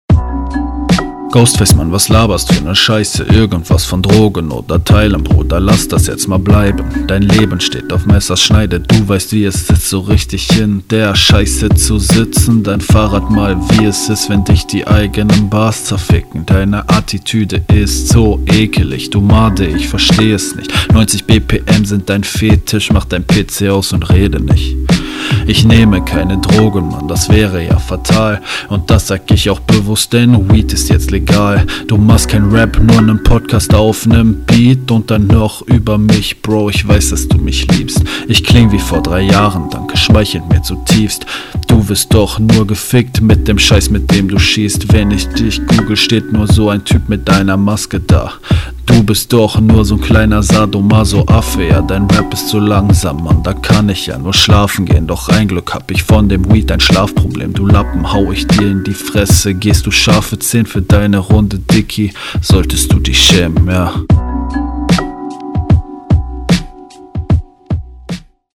Äh ja das ist wahrscheinlich der am schlechtesten gemischte Track der BRB.
Also der Flow stimmt teilweise echt gar nicht, das hat der Gegner deutlich nicer hingekriegt.
Ähnlich monoton wie der Gegner aber mit uncoolerer Stimme und mehr Unsicherheiten.